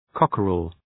{‘kɒkərəl}